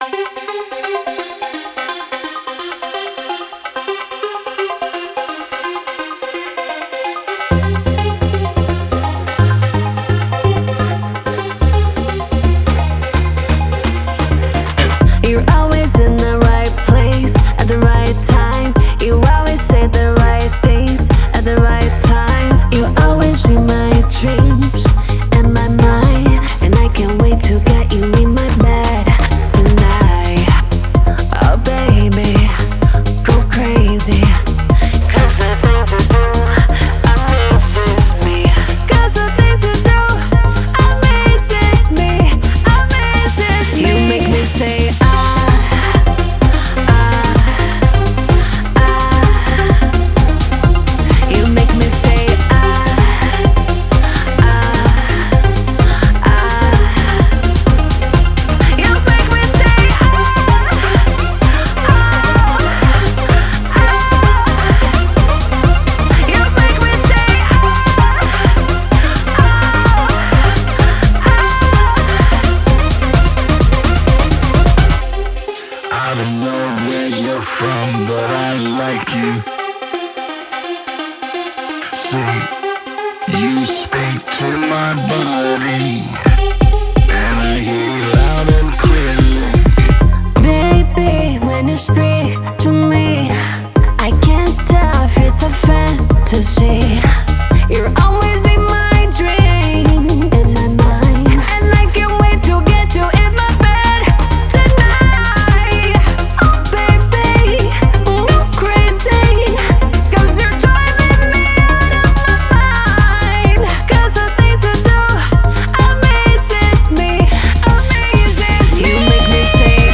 это энергичная танцевальная композиция в жанре Eurodance